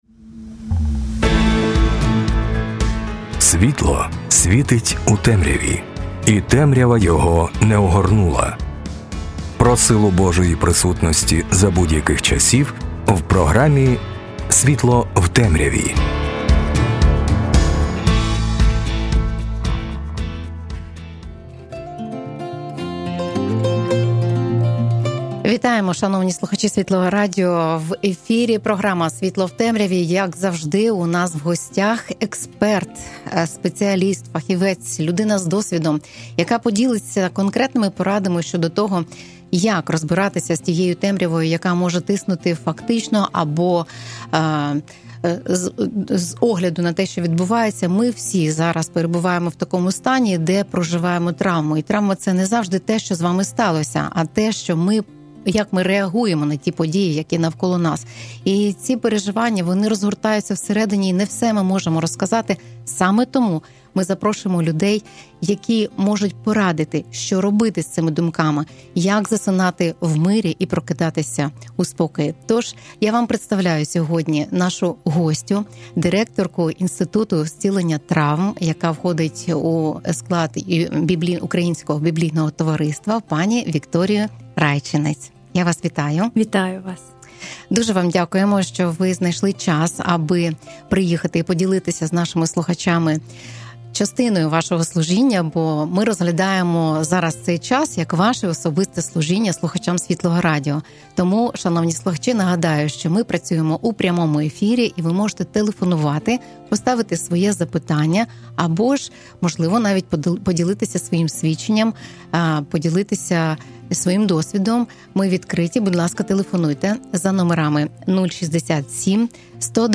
Сьогодні, коли війна торкнулася кожного українця, тема психологічного зцілення є як ніколи важливою. В розмові з гостею почуєте конкретні кроки, які допомагають людині бути цілісною.